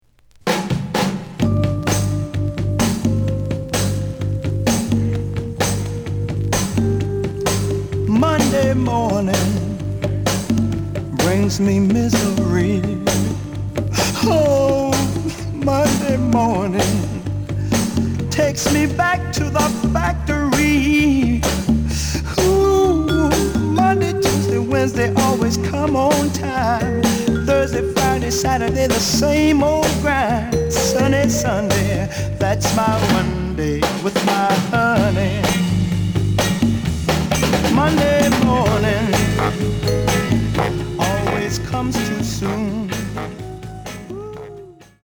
The listen sample is recorded from the actual item.
●Genre: Rhythm And Blues / Rock 'n' Roll
Slight edge warp.